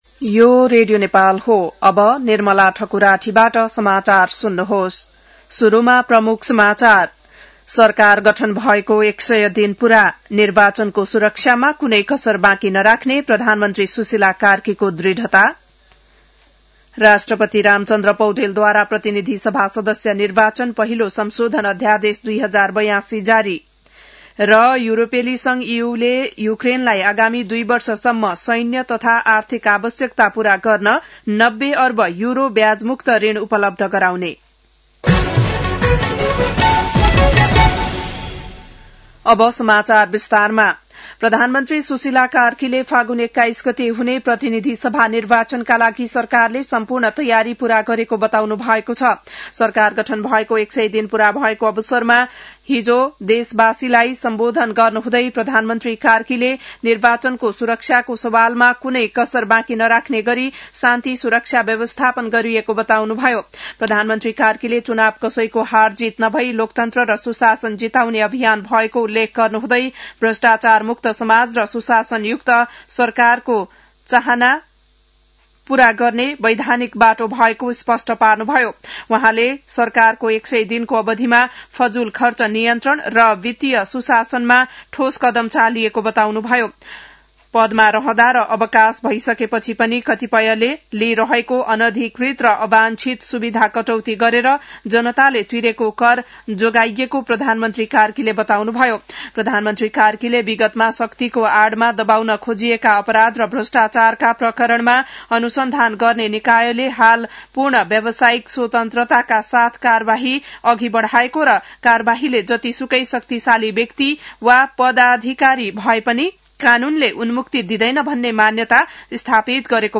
An online outlet of Nepal's national radio broadcaster
बिहान ९ बजेको नेपाली समाचार : ५ पुष , २०८२